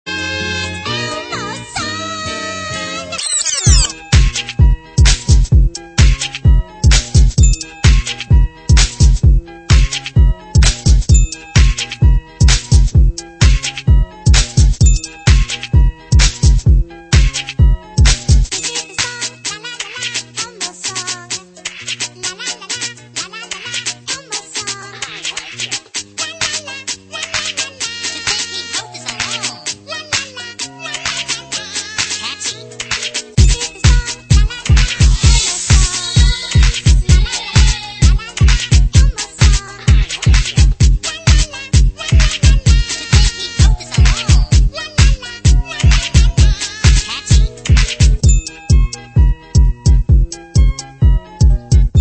• Dance Ringtones